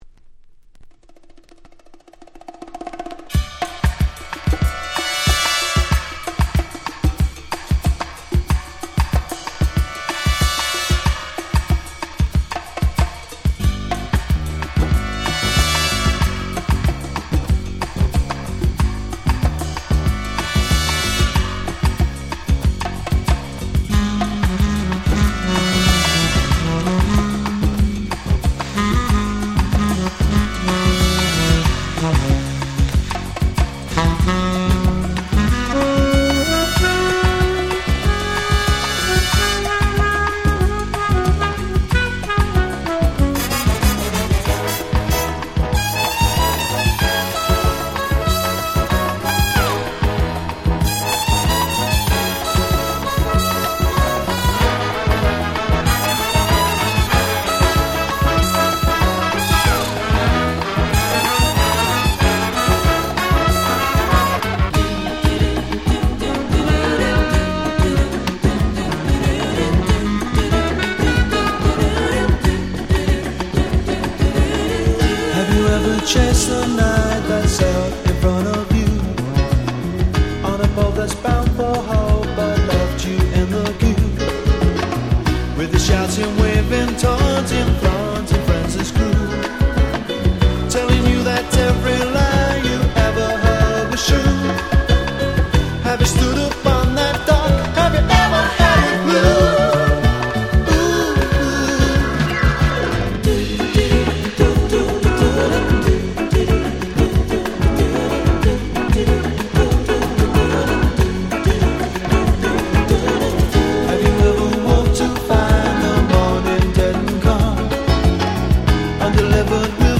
86' Very Nice Bossa R&B !!
超〜爽やか！！
Bossa風味R&B超人気盤！！
ボッサ